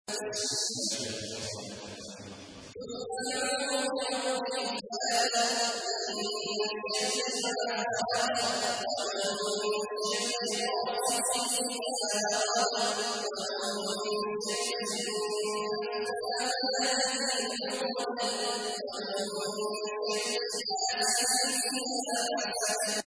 تحميل : 113. سورة الفلق / القارئ عبد الله عواد الجهني / القرآن الكريم / موقع يا حسين